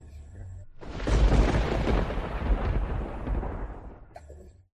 Thunder Clap
Thunder Clap is a free sfx sound effect available for download in MP3 format.
010_thunder_clap.mp3